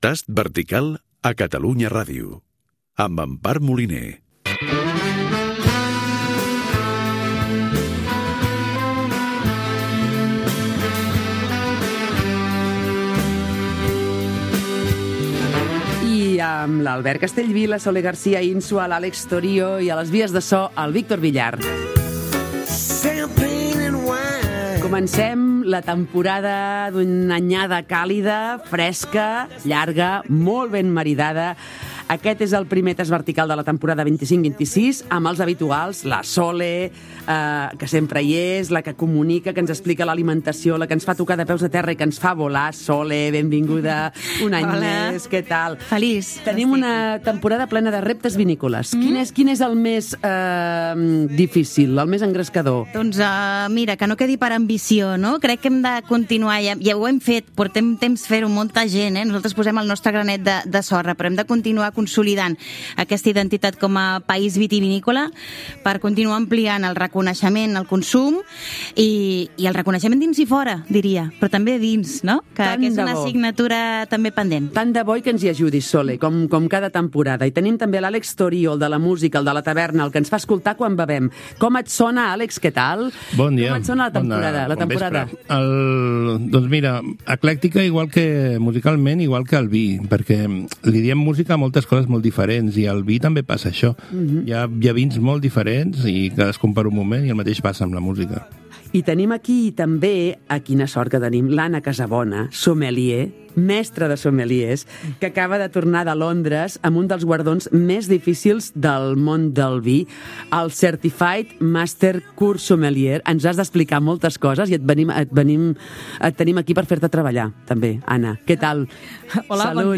Careta del programa, equip i presentació dels col·laboradors del programa sobre vinyes i vins. La verema
Divulgació